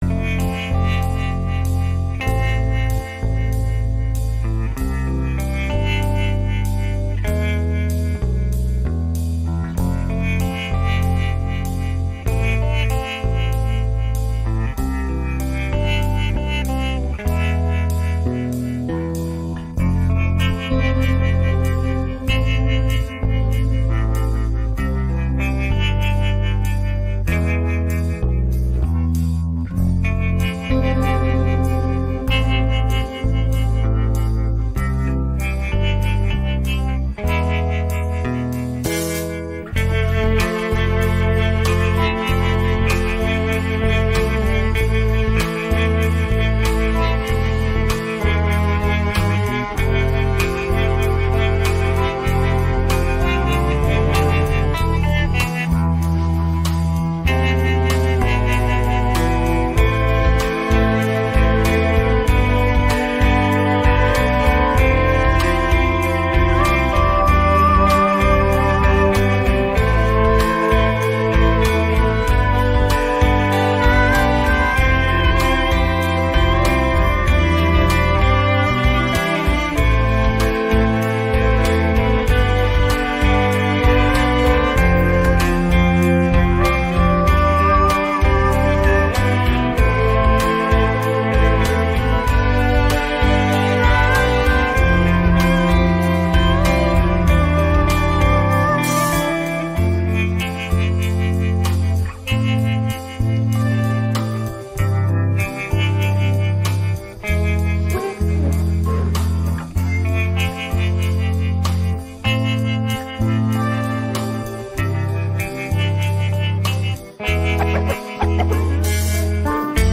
rock караоке